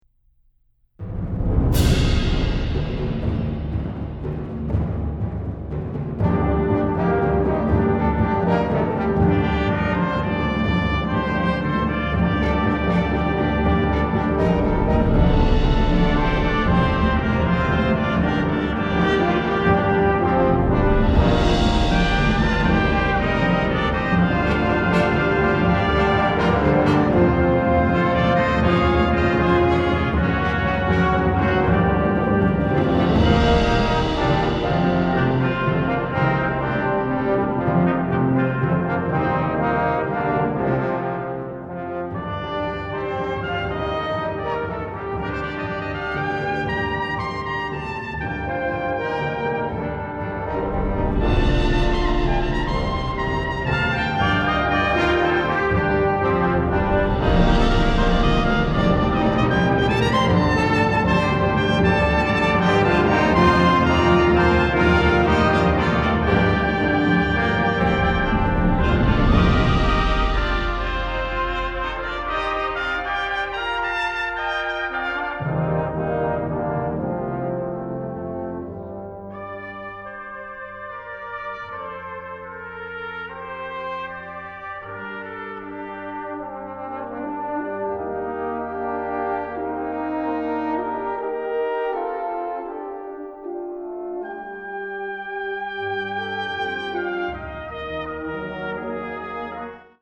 ★ 銅管與打擊樂器的緊密結合，無比精確與共鳴！ ★ 優異錄音細節重現，讓您感受到來自肺腑的悸動！